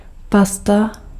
Ääntäminen
IPA : /peɪst/ US : IPA : [peɪst]